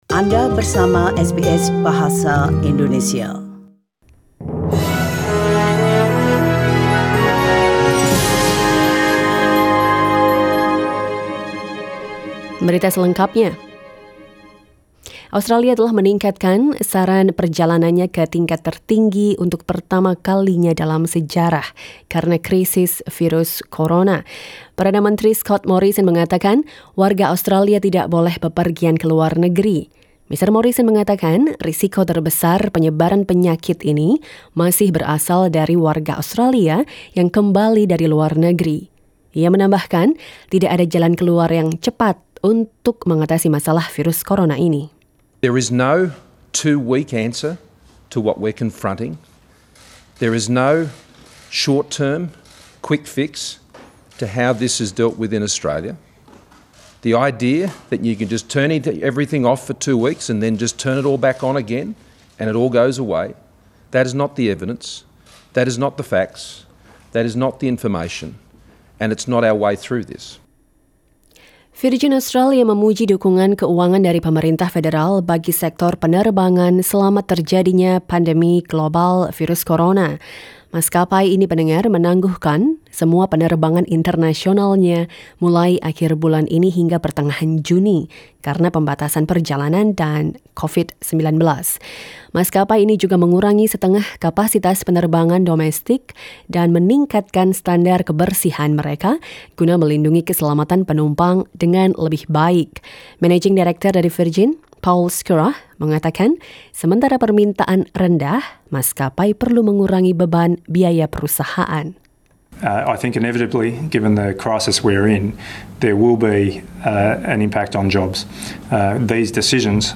SBS Radio news in Indonesian 18 March 2020